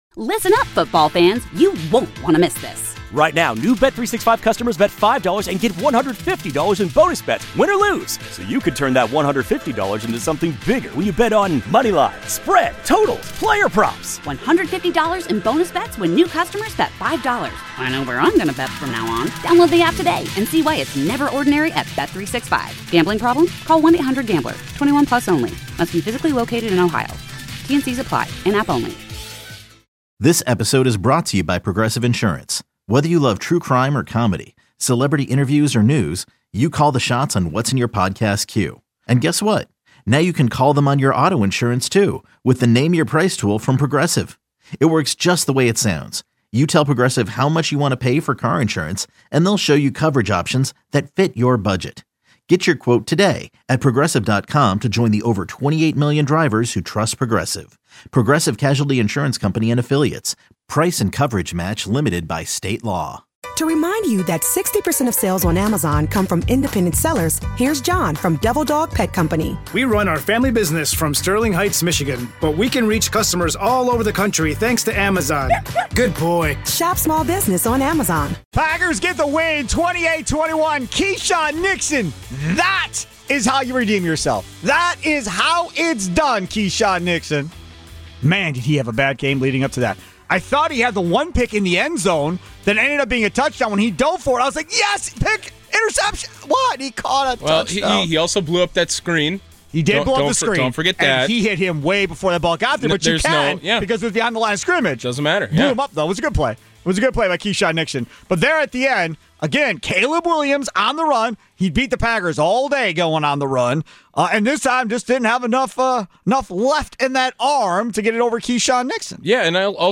In this insightful interview